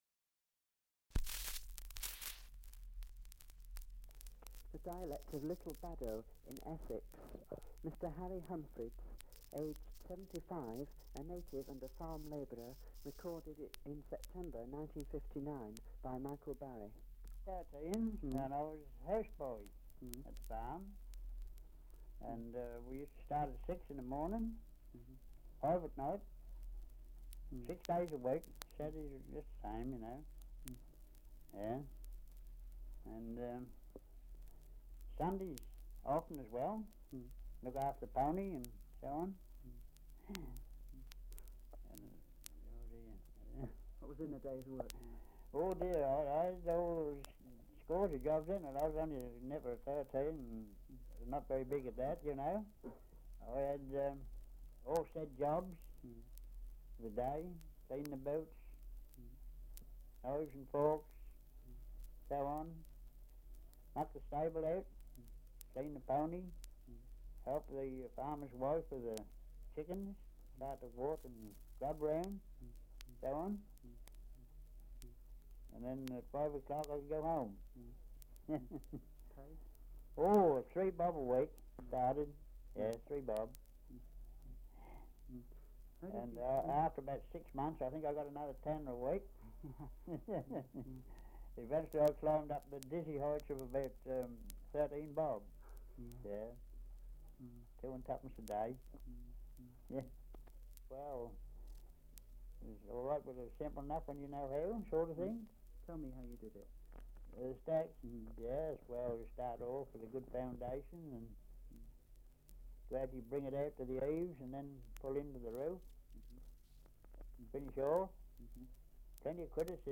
Survey of English Dialects recording in Little Baddow, Essex
78 r.p.m., cellulose nitrate on aluminium